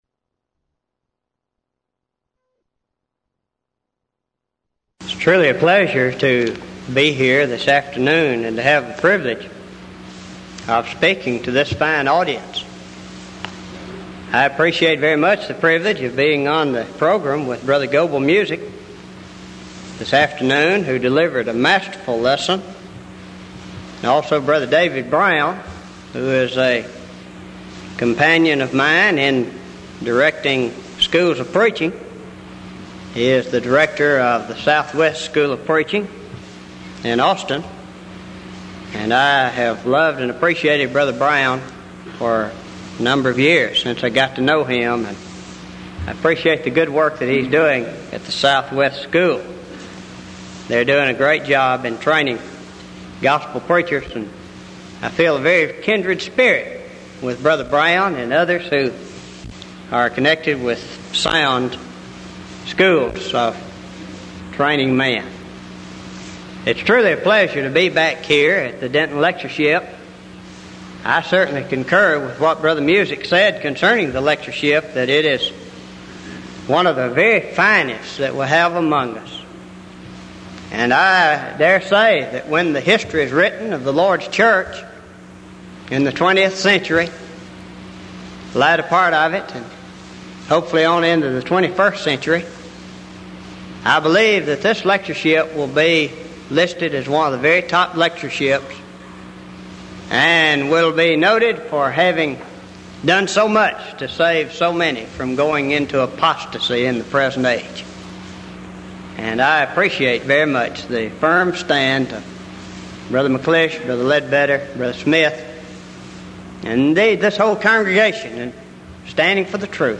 Event: 1986 Denton Lectures
this lecture